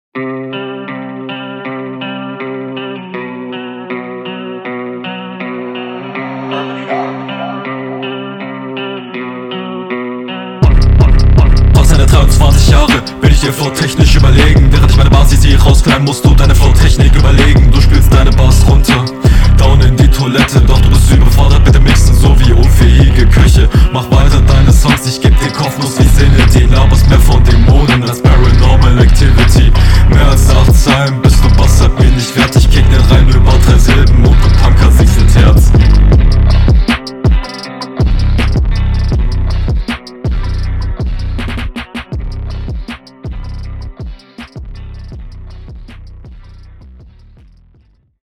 Battle Rap Bunker